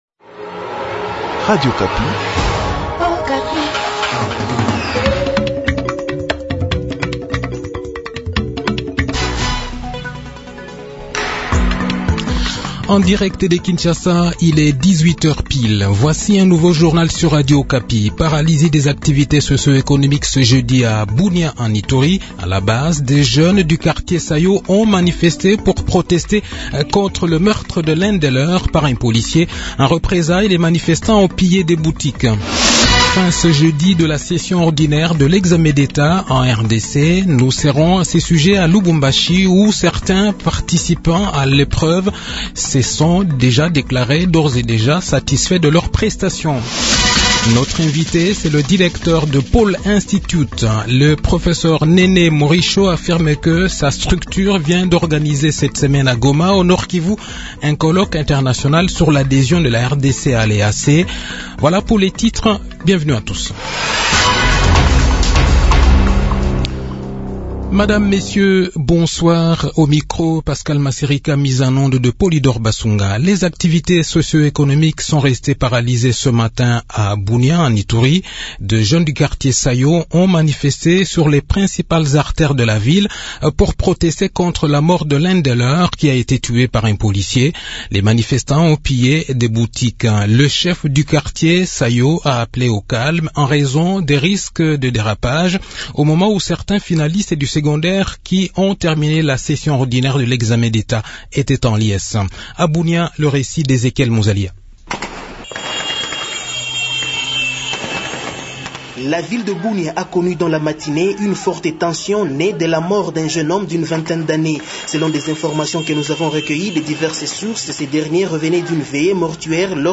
Le journal de 18 h, 29 Juin 2023